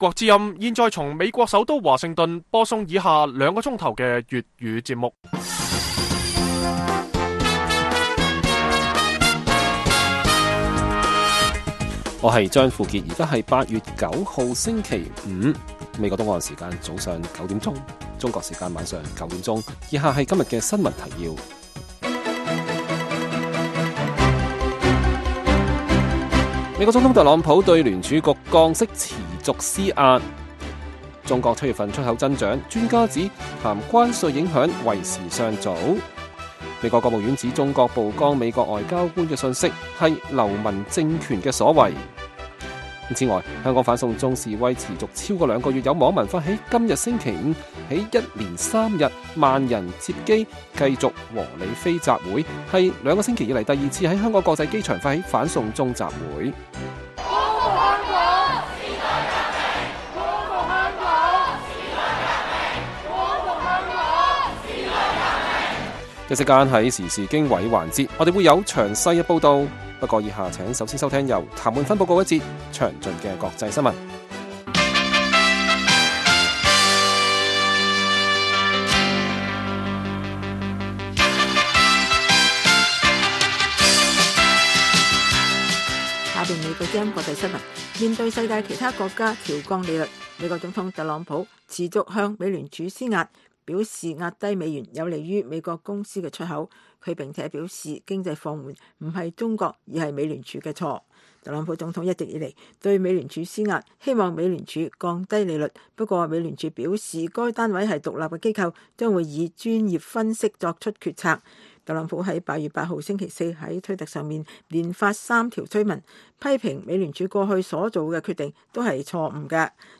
粵語新聞 晚上9-10點
北京時間每晚9－10點 (1300-1400 UTC)粵語廣播節目。